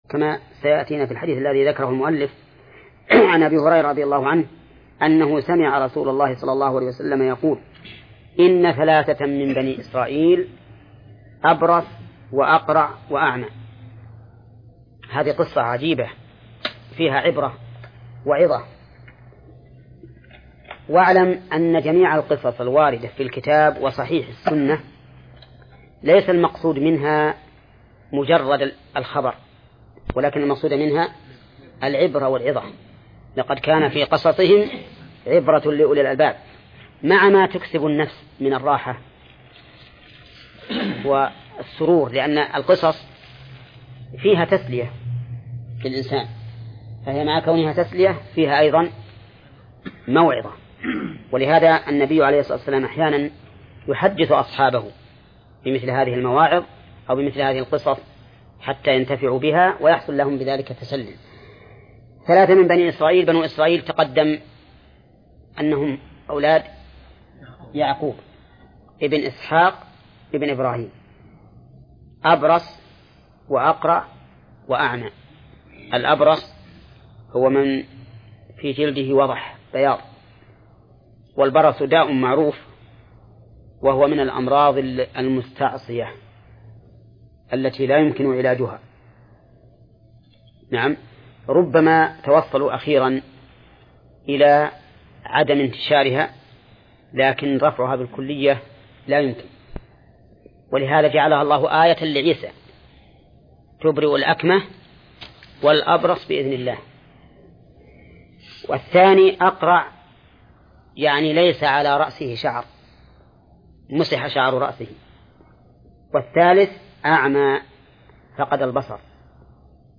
درس (43) / المجلد الثاني : من صفحة: (284)، قوله: (وعن أبي هريرة: ..).، إلى صفحة: (305)، قوله: (قال ابن حزم: اتفقوا على تحريم ..).